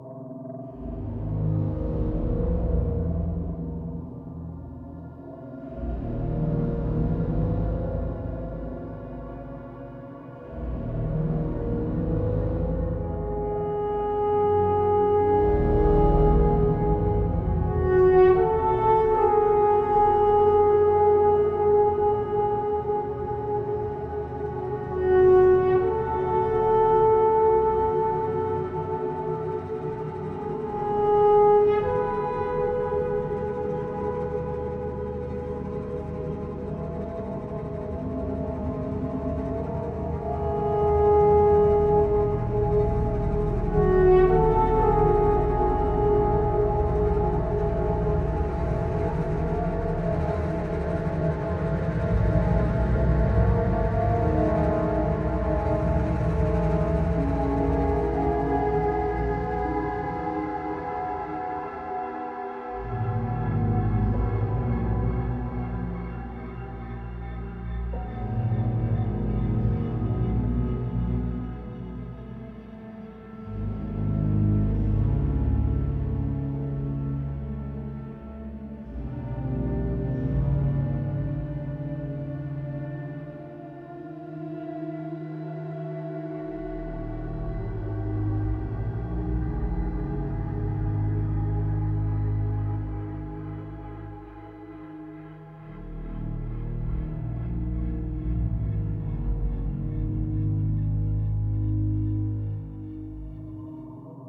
Ambience 3 - The Chamber - (Loop).wav